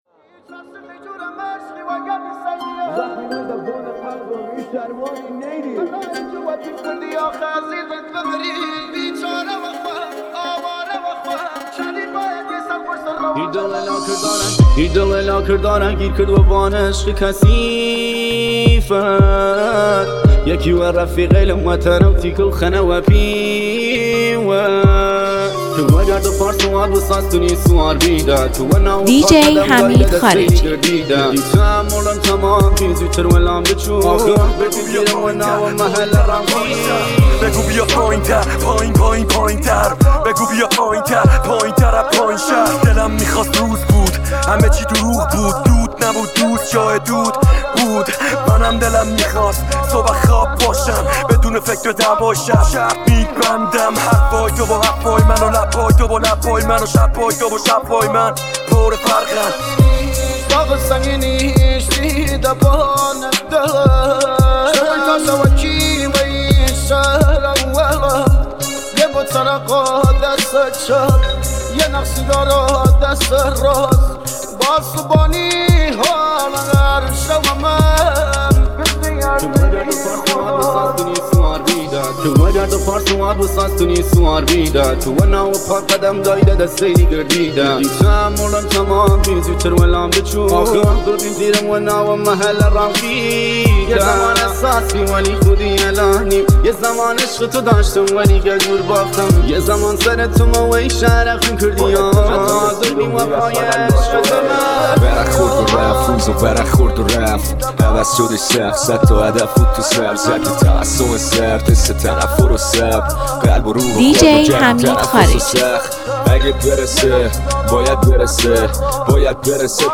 ریمیکس جدید و پرانرژی
با ترکیبی بی‌نظیر از سبک کردی و رپ